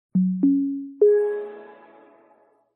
startup.mp3